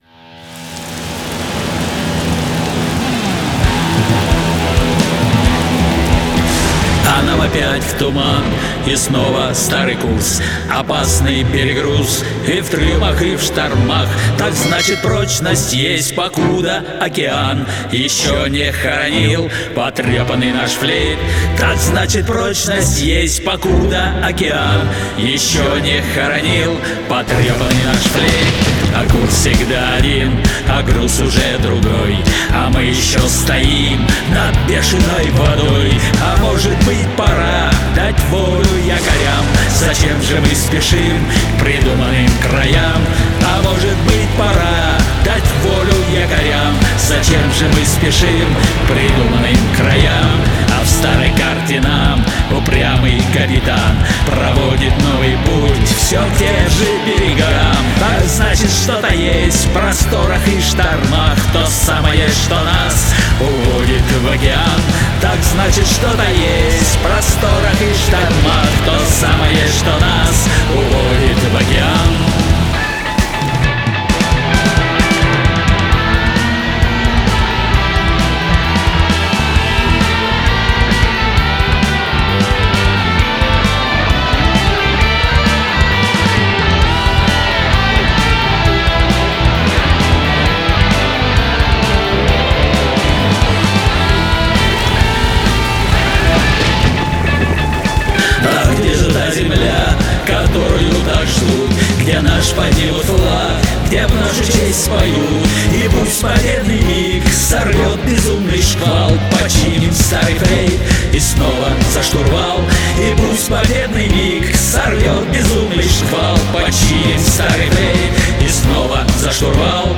голос, гитары